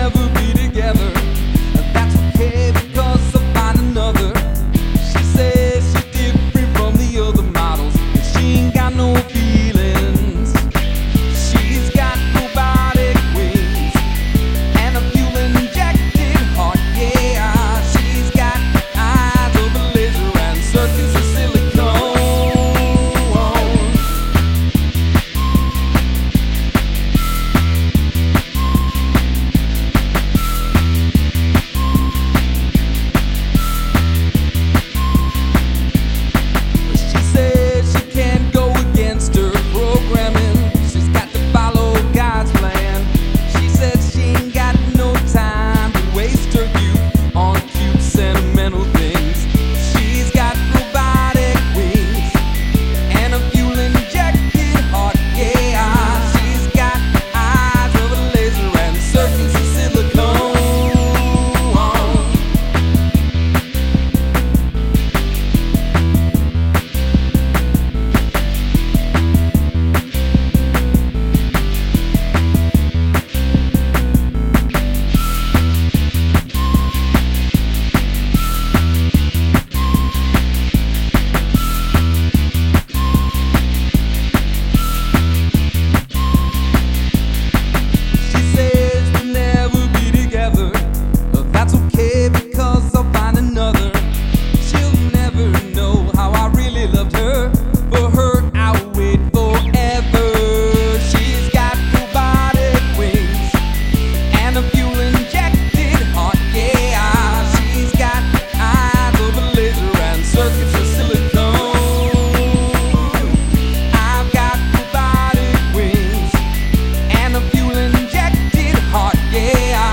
Specifically, at about the 17-second mark, the song skips ahead to about the 40-second mark.